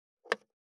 513切る,包丁,厨房,台所,野菜切る,咀嚼音,ナイフ,調理音,まな板の上,料理,
効果音